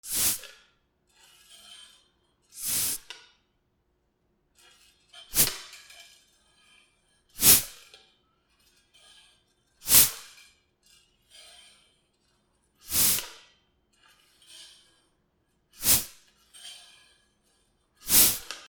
/ E｜乗り物 / E-45 ｜自転車
自転車空気入れ